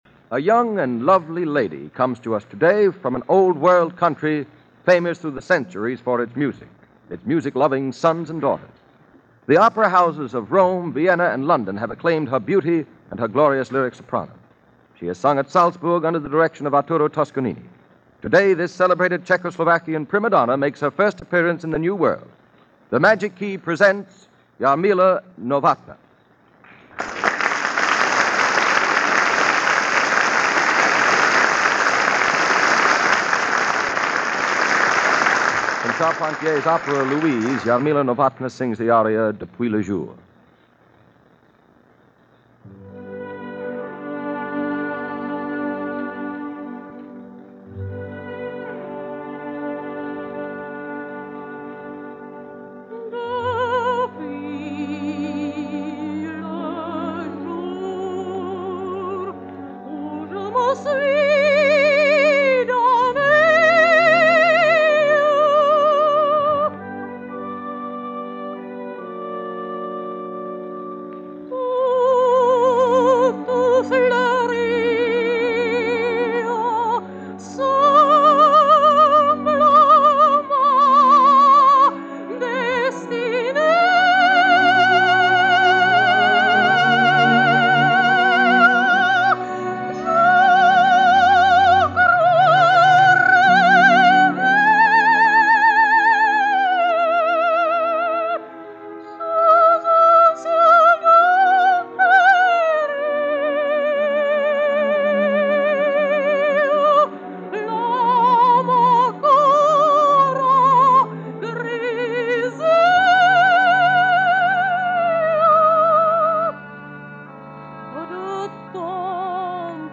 Jarmila Novotna - Stunning voice and staggering looks made for audible gasps.
Continuing the series of legendary opera stars performing on the radio by way of the landmark RCA Magic Key Program in the 1930s, here is the American radio debut of Czech opera sensation Jarmila Novotna, from a broadcast made on April 30, 1939.
Because the program was cut short this particular week, owing to President Roosevelt’s upcoming address from the Opening of The New York World‘s Fair, Novotna only sings two pieces; Depuis le Jour from Charpentier’s Louise and a Czech Folk Song.
Both pieces are accompanied by the NBC Studio Orchestra conducted by Frank Black.